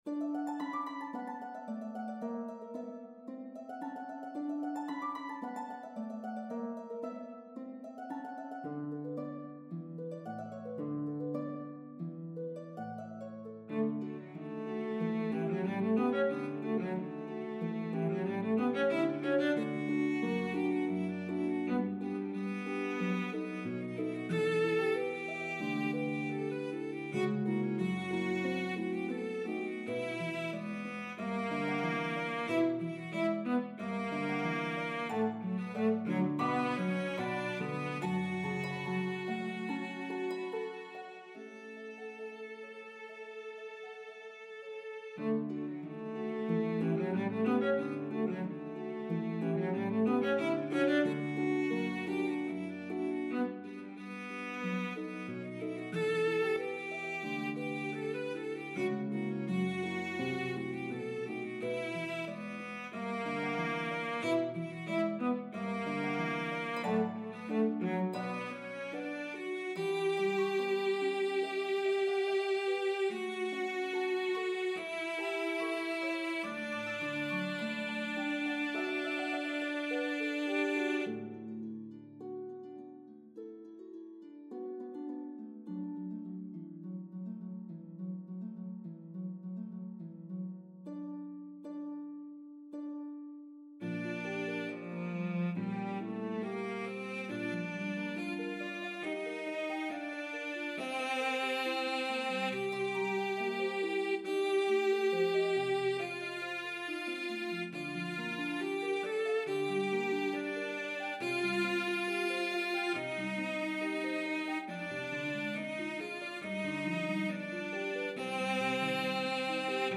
It is equally beautiful played by cello and harp.